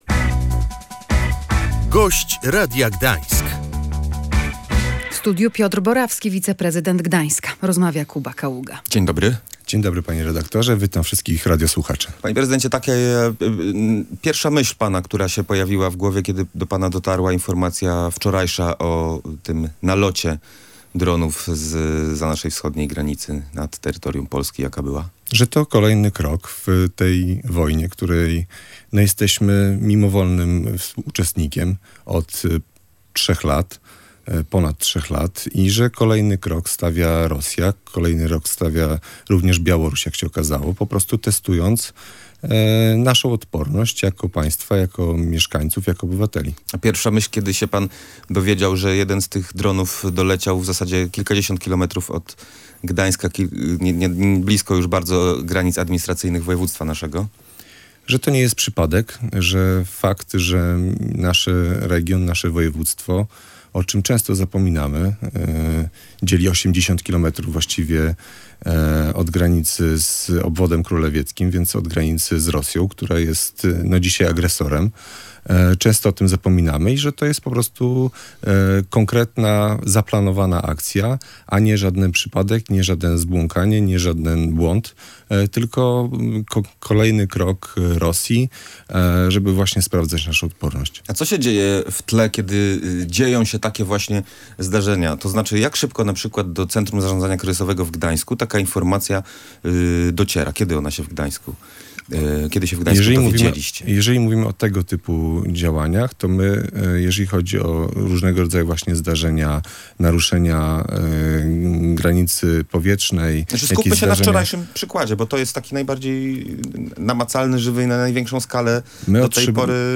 W Gdańsku jest kilkaset tysięcy miejsc schronienia na wypadek zagrożenia – zapewniał gość Radia Gdańsk, wiceprezydent Gdańska Piotr Borawski.